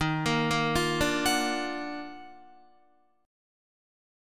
EbmM9 chord